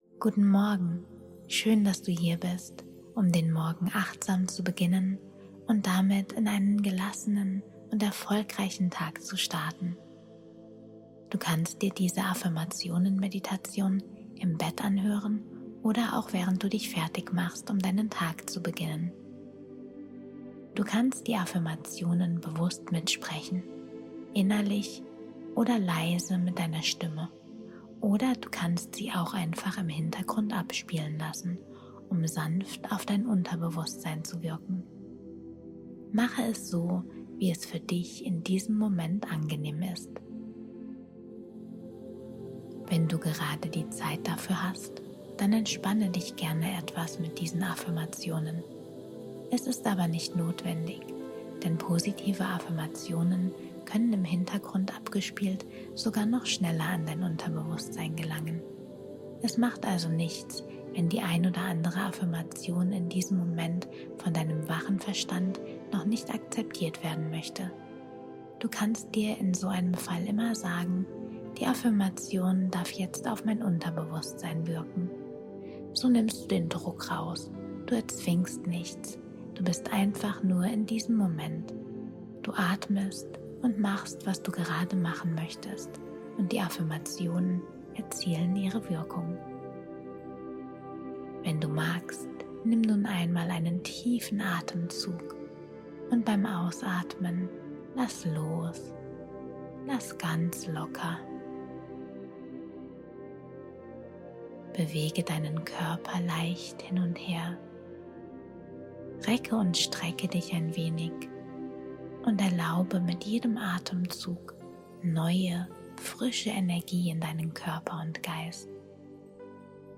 Stressfrei-Garantie - 1Hz Binaural Beat löscht alle Sorgen